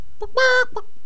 chicken_ack2.wav